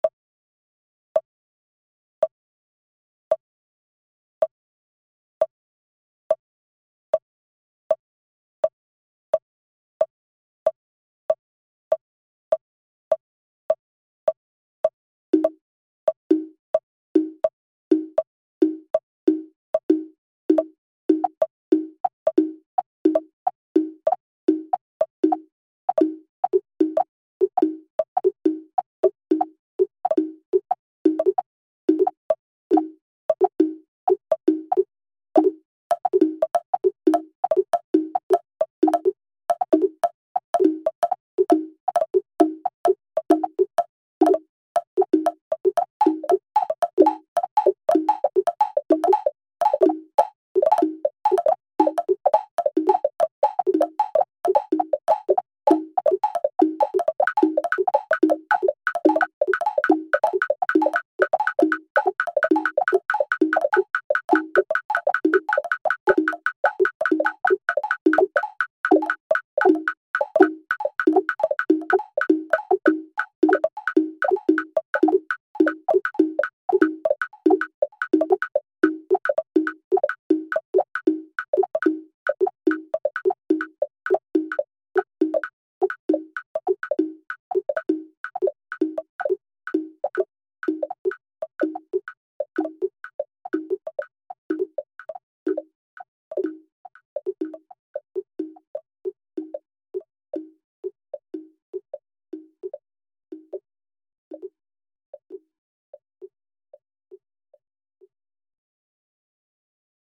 In the process, 8 different mokugyo sounds, wooden fish percussion bodies used by Buddhist monks for rhythmic recitation, are heard. The metronomically pulsating tempi, which are independent of each other, can be influenced in their speed by the length of the shadowing and produce polyrhythmic overlaps as well as interesting percussive shifts.
Mokugyo8-Rec-loud-2023.mp3